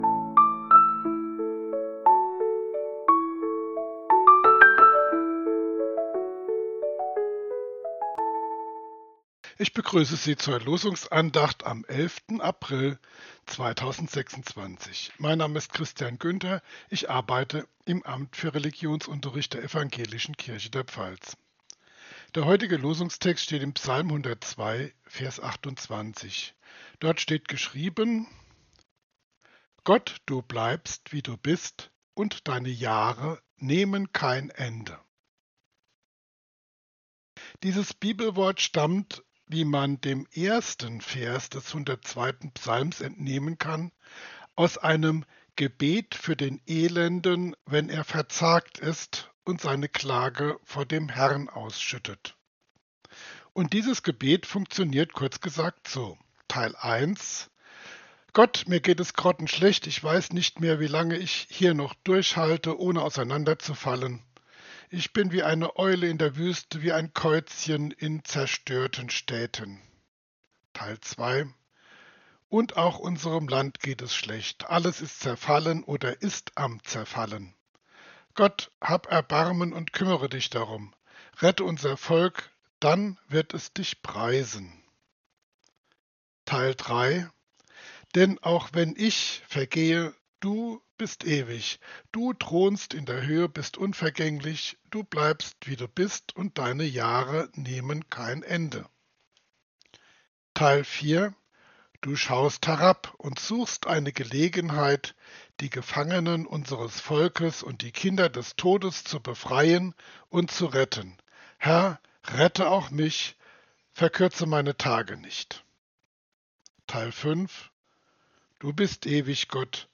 Losungsandacht für Dienstag, 21.04.2026